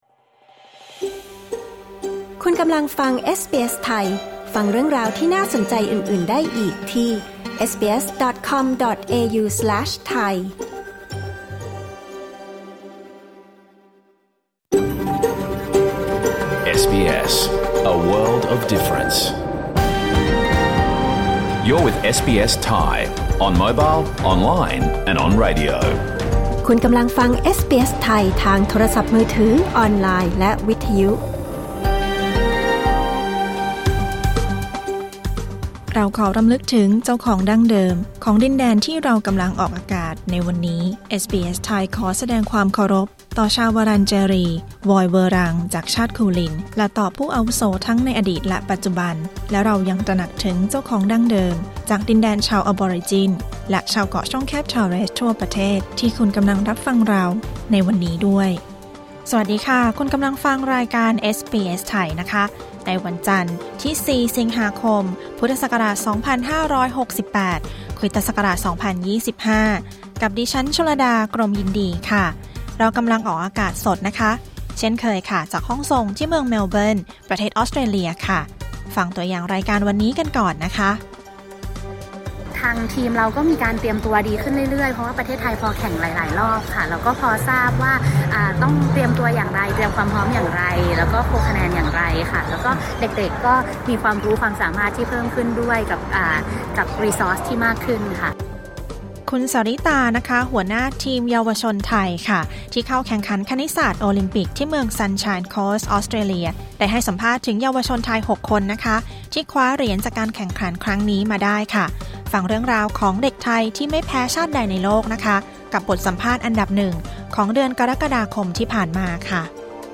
รายการสด